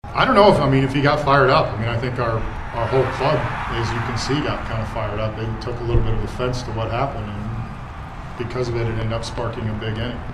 Shelton says the Pirates fed off the emotion after Brian Reynolds was hit by a pitch and McCutchen was almost beaned in the head.